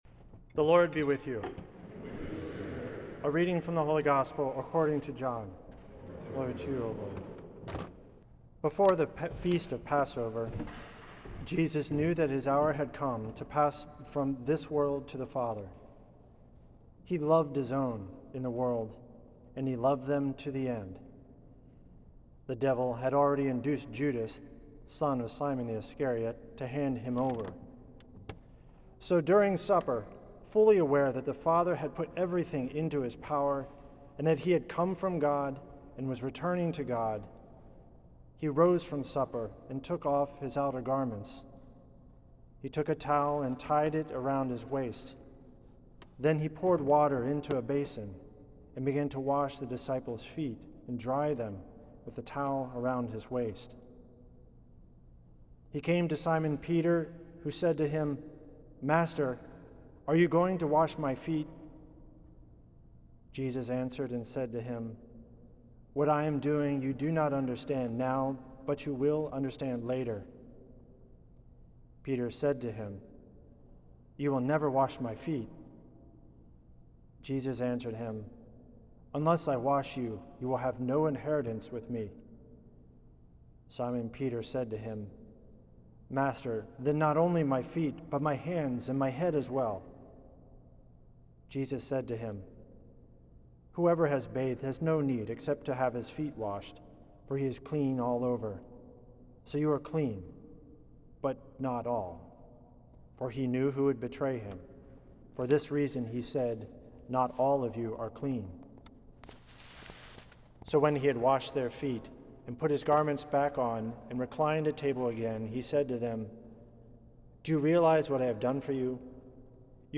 A Holy Thursday homily